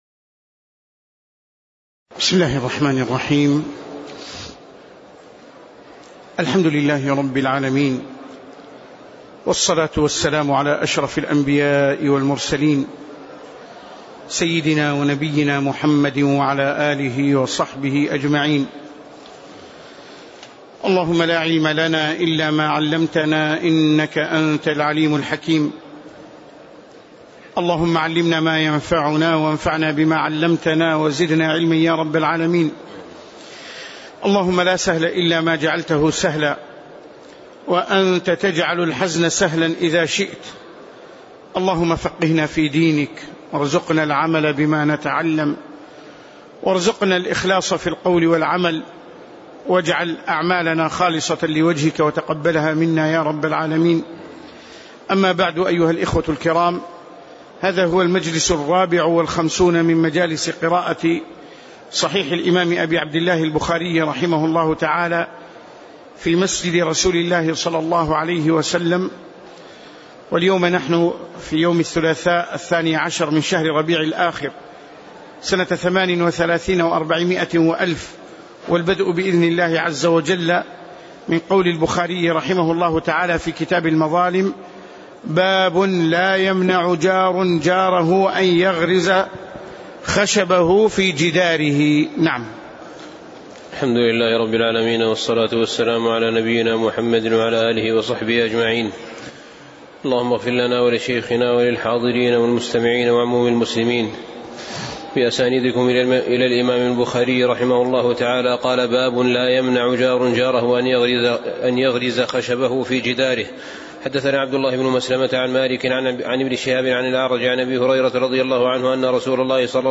تاريخ النشر ١٢ ربيع الثاني ١٤٣٨ هـ المكان: المسجد النبوي الشيخ